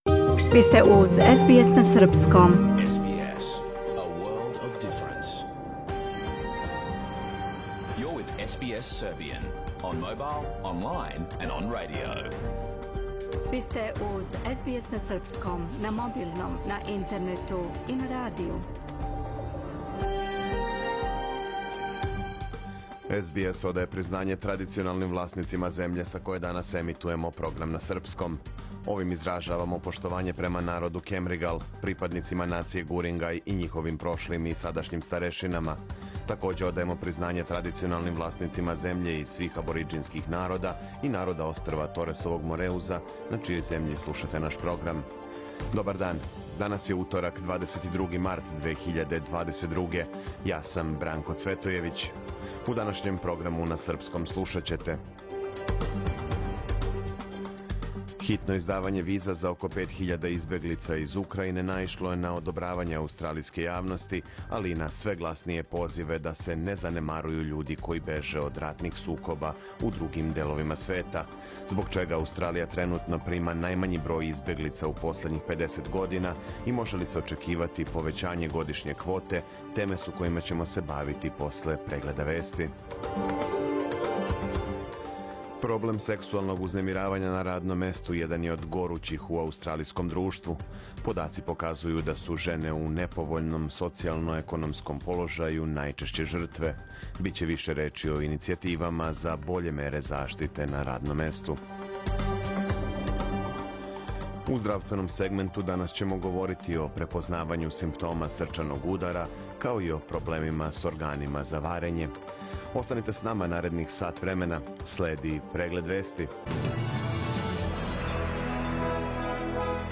Програм емитован уживо 22. марта 2022. године
Ако сте пропустили нашу емисију, сада можете да је слушате у целини као подкаст, без реклама.